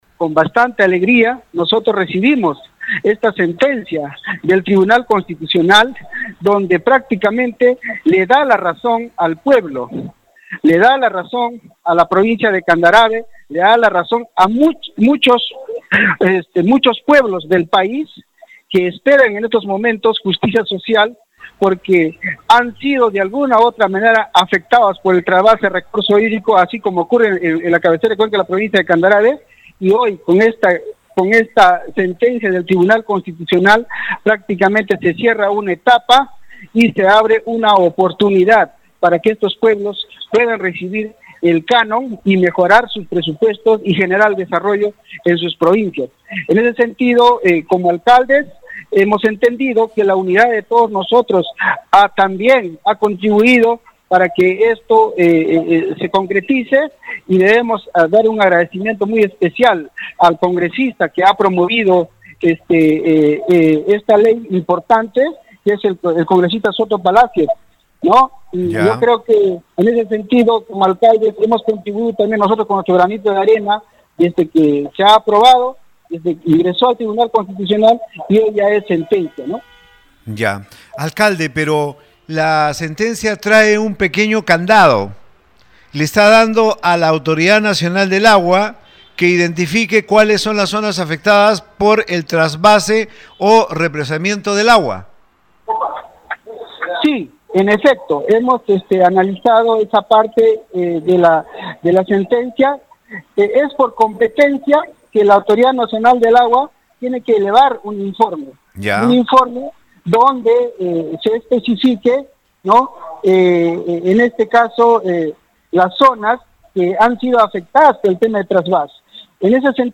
rafael-vega-alcalde-de-candarave.mp3